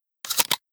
sounds / weapons / ks23 / load.ogg
load.ogg